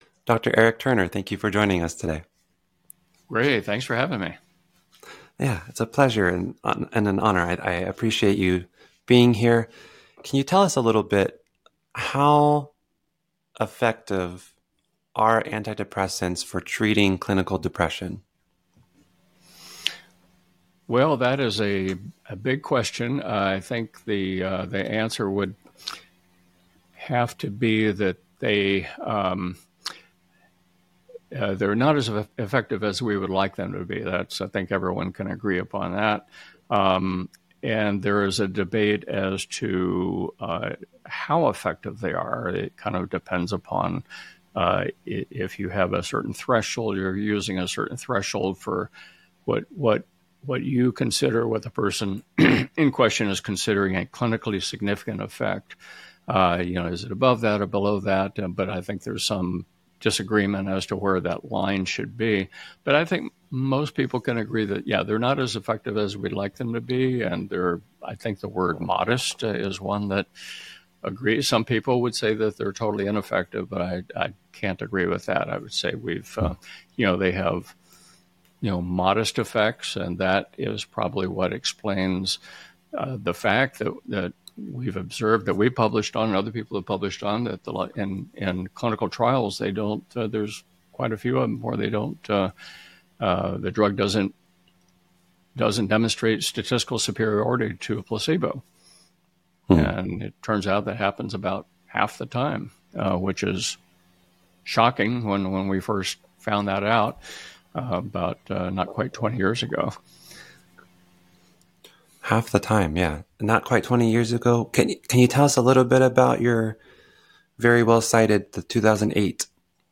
A student in Clinical Psychology having conversations with students, professors, and professionals in psychology.